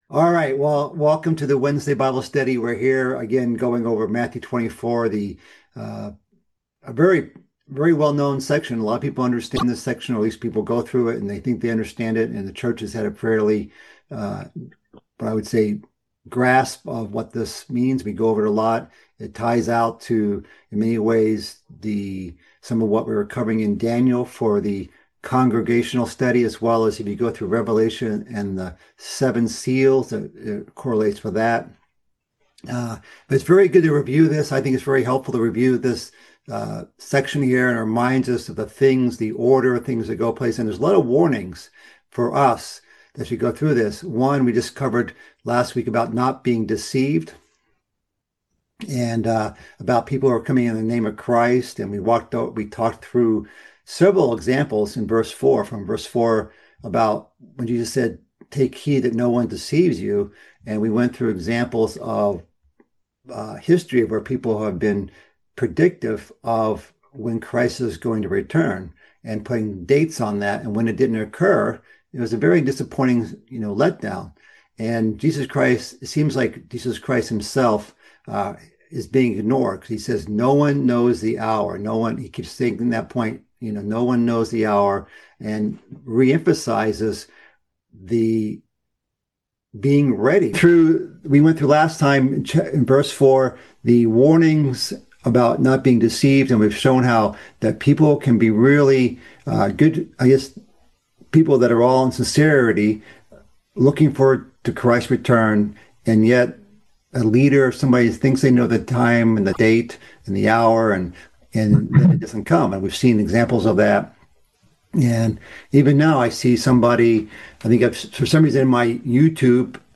This is the seventh part of a mid-week Bible study series covering Christ's fifth discourse in the book of Matthew.